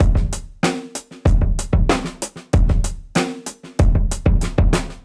Index of /musicradar/dusty-funk-samples/Beats/95bpm
DF_BeatB_95-01.wav